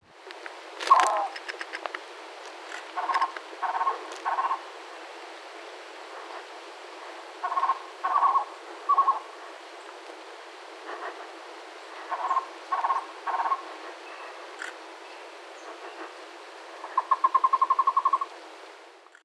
Cuban Trogon
I was photographing this trogon endlessly, and finally thought to make a video, so this recording was via my Canon R5. The photo is of this bird, made during his bout of singing.
Recorded on Feb. 18, 2025 Recorded in Artemisa, Cuba I was photographing this trogon endlessly, and finally thought to make a video, so this recording was via my Canon R5.
Cuban_Trogon.mp3